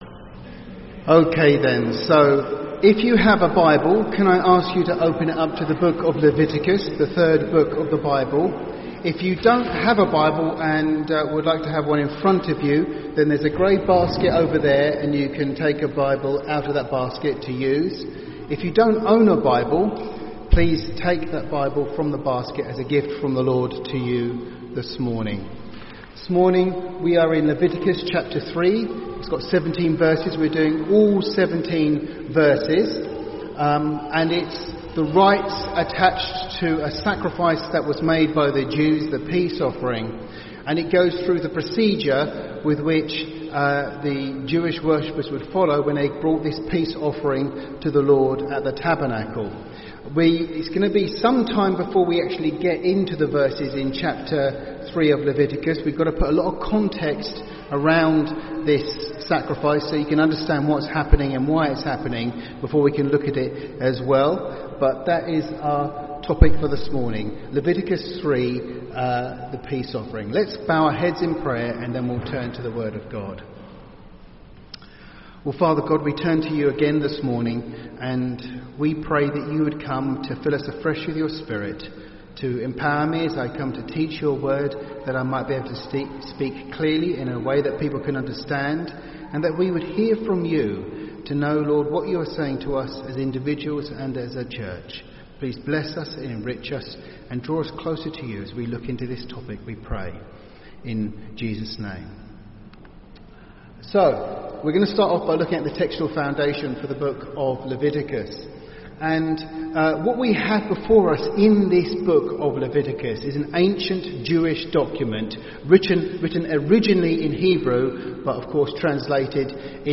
Listen to Audio Sermon Gospel Israel Scriptures Leviticus To the Israelite in the wilderness, worship was all about sacrifice.